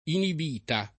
— voce antiq. per «inibizione» — solo con pn. piana, come il part. pass. di inibire ; non con doppia pn. come esibita